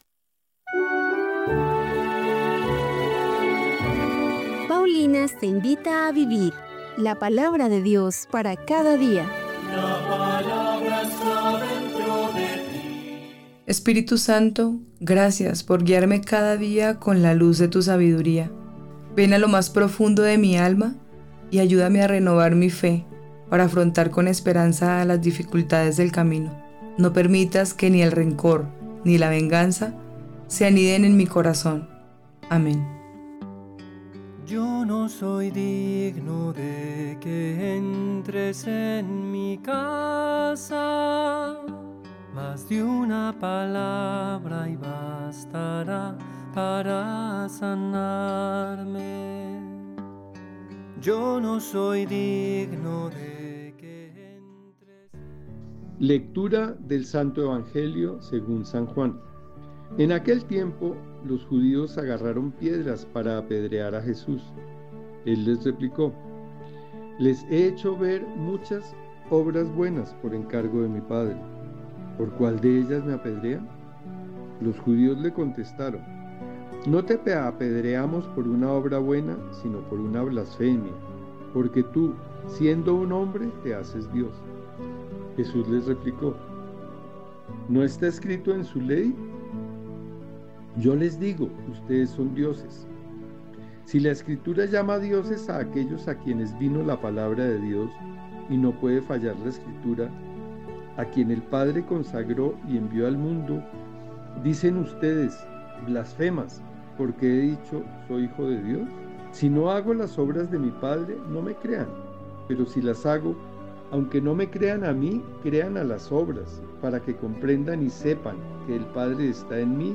Lectura de los Hechos de los Apóstoles 5, 27-33